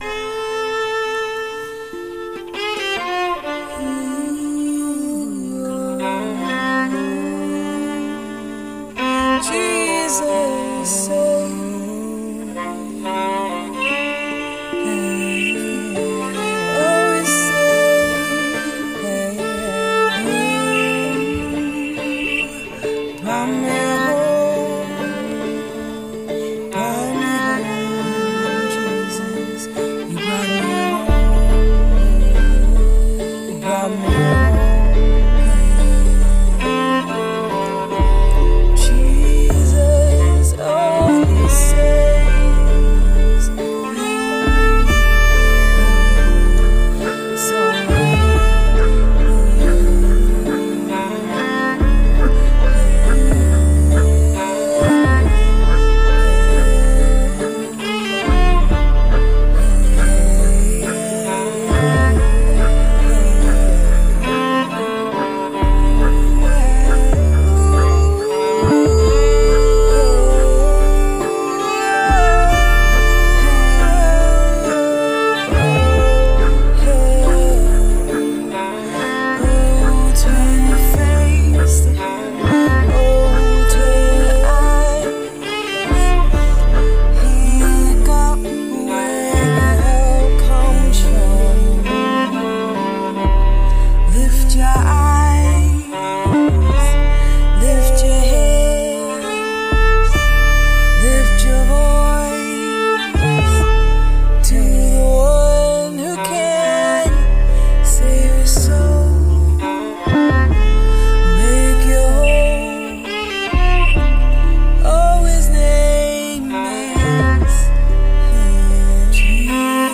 Jesus, No Other Name (Old Country Rav Vast & Beats Sessions)